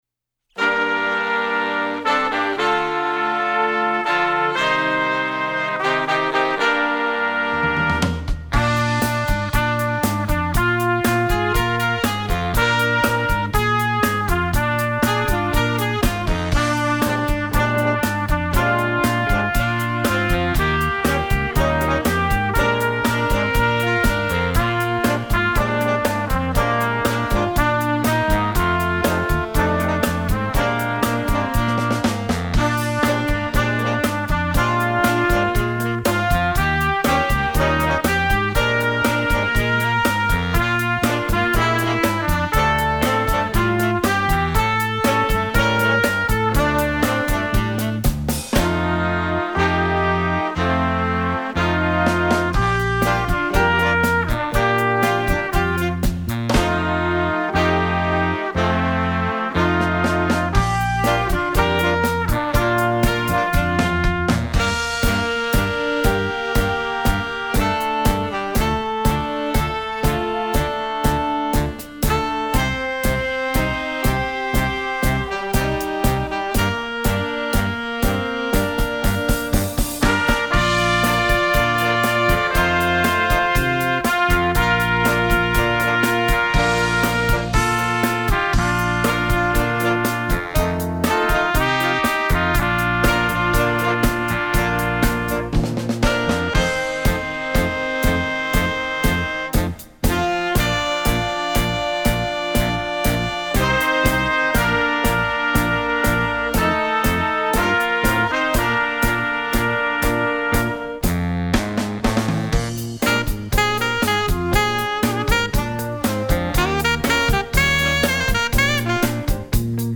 jazz, sacred